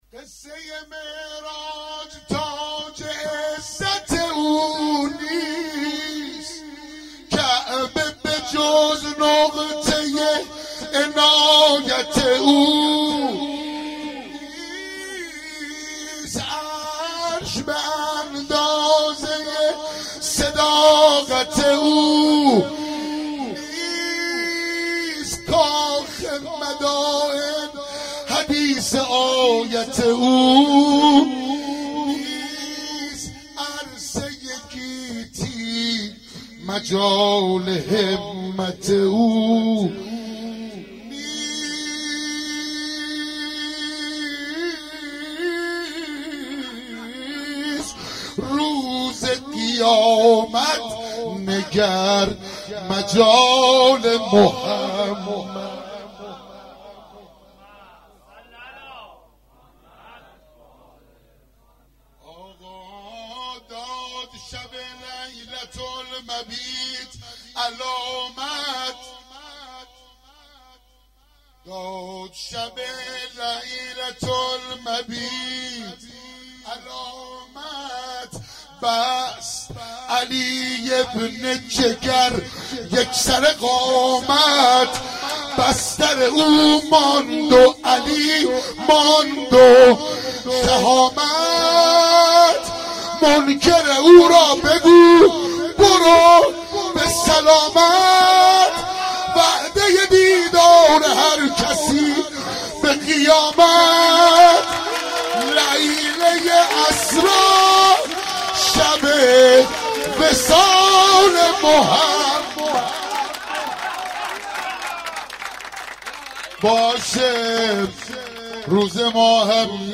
گلچین مولودی مبعث پیامبر